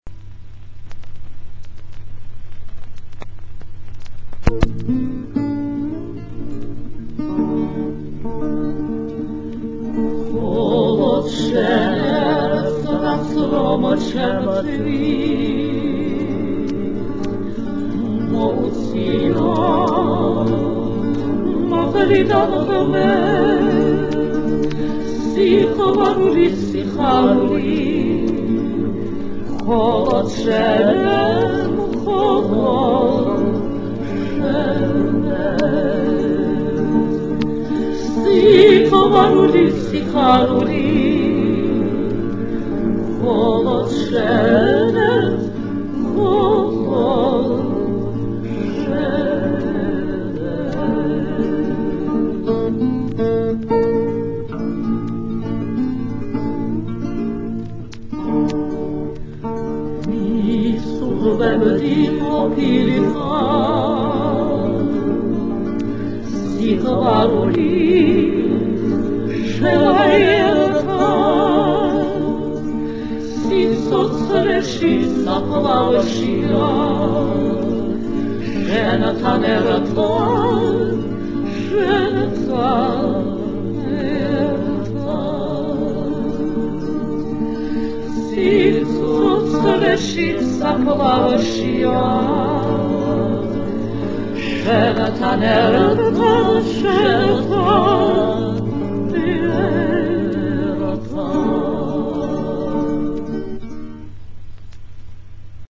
ვოკალური კვარტეტი დების